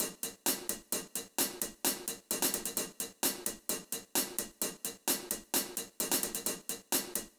130_sabian_jackd+verb_HH_1.wav